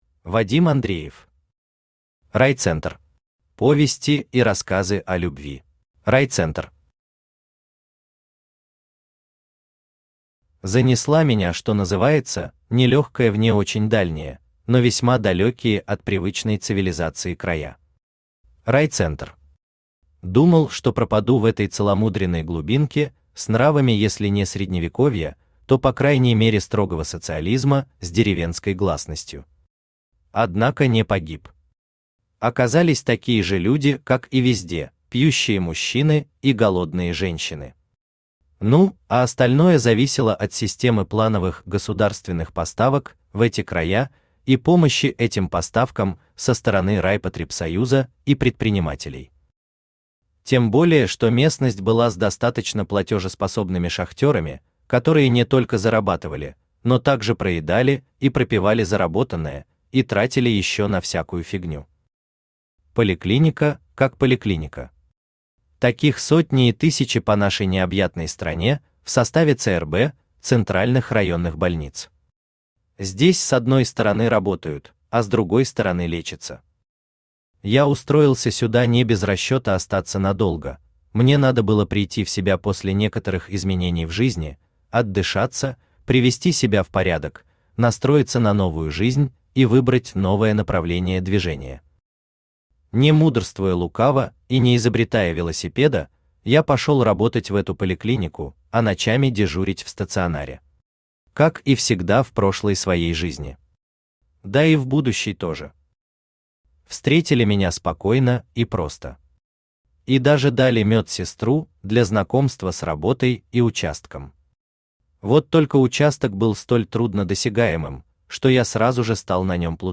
Аудиокнига Райцентр. Повести и рассказы о любви | Библиотека аудиокниг
Повести и рассказы о любви Автор Вадим Андреев Читает аудиокнигу Авточтец ЛитРес.